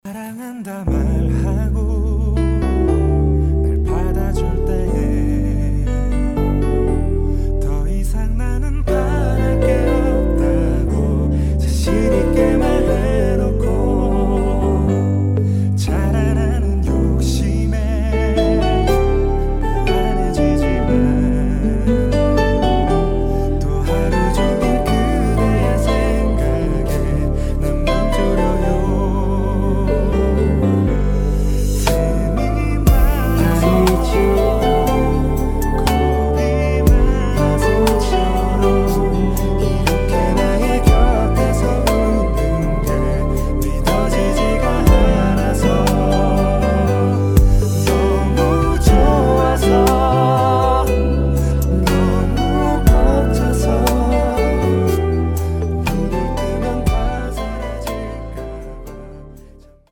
음정 원키 3:29
장르 가요 구분 Voice MR
보이스 MR은 가이드 보컬이 포함되어 있어 유용합니다.